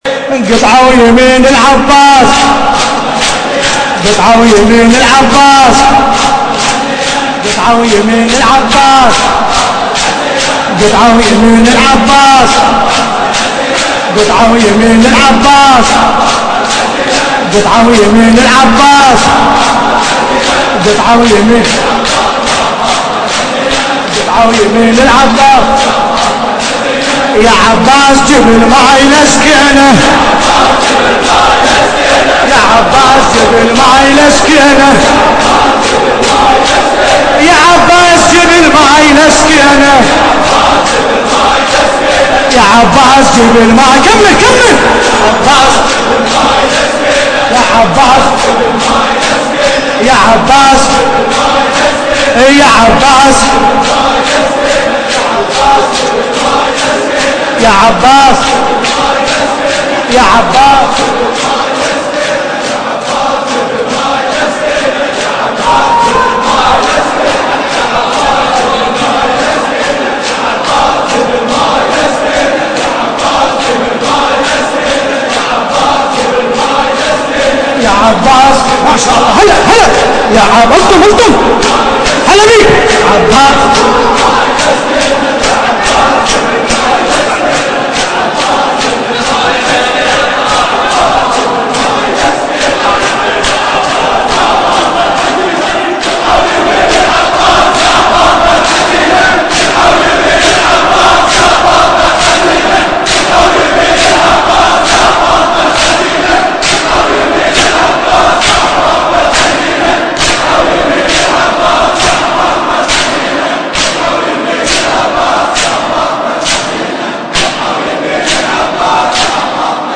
القارئ: باسم الكربلائي التاريخ: الليلة السابع من شهر محرم الحرام عام 1434 هـ - الكويت .